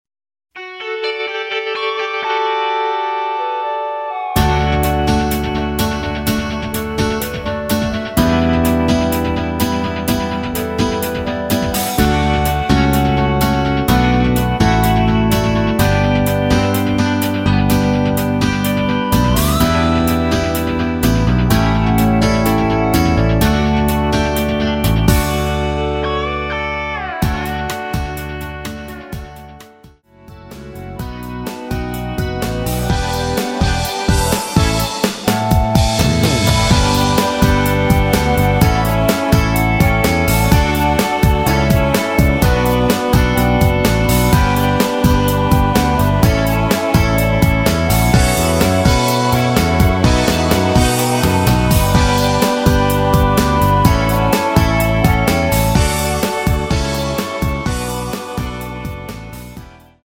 ◈ 곡명 옆 (-1)은 반음 내림, (+1)은 반음 올림 입니다.
음정과 박자 맞추기가 쉬워서 노래방 처럼 노래 부분에 가이드 멜로디가 포함된걸
앞부분30초, 뒷부분30초씩 편집해서 올려 드리고 있습니다.
중간에 음이 끈어지고 다시 나오는 이유는